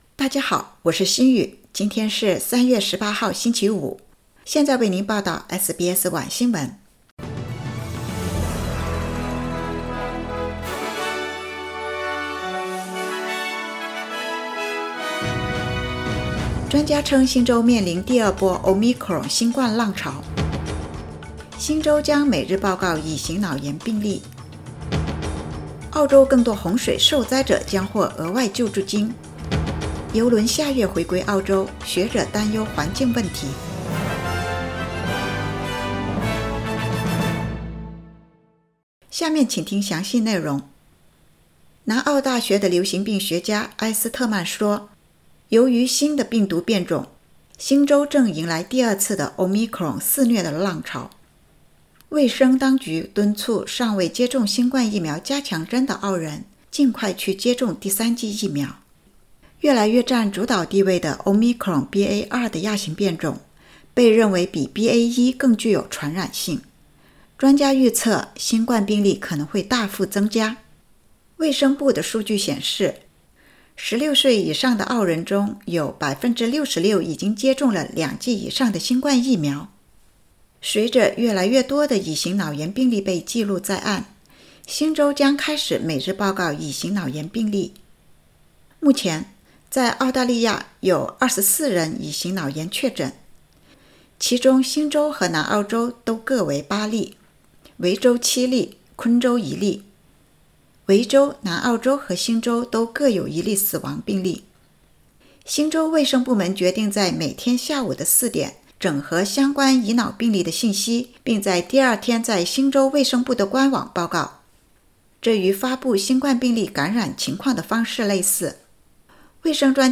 SBS晚新闻（3月18日）